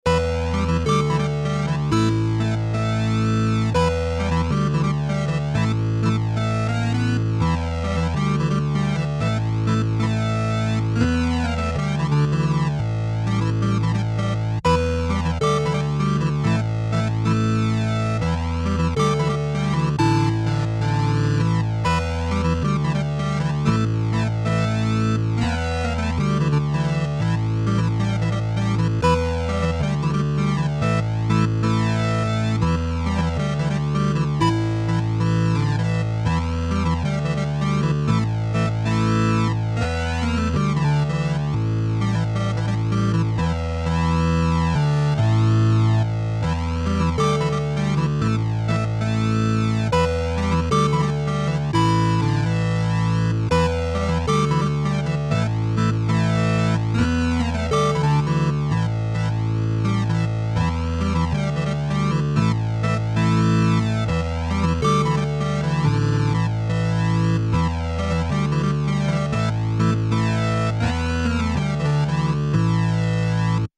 8 bit Gaming Musik
Tempo: schnell / Datum: 15.08.2019